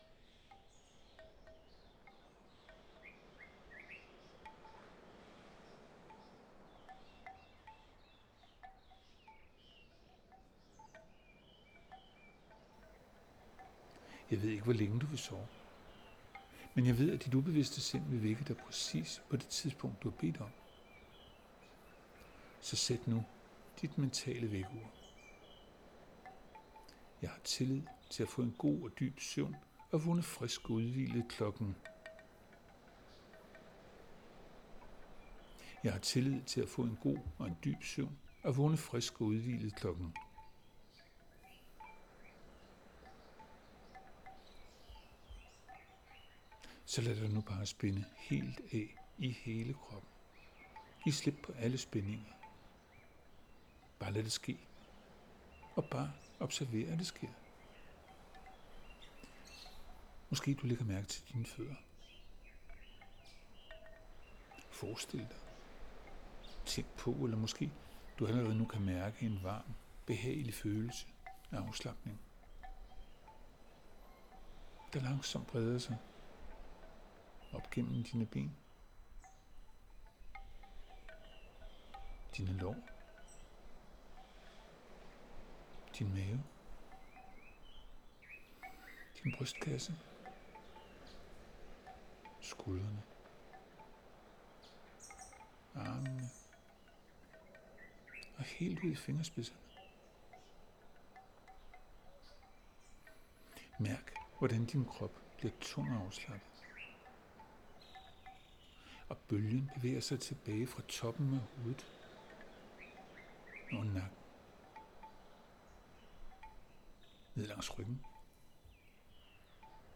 Vejen til en godt og forfriskende søvn Denne lydfil hjælper dig til at få en god og forfriskende søvn. Du bliver guidet roligt og langsomt ind i den dybe og gode søvn.
Der anvendes hypnotiske teknikker og en blid baggrundsmusik.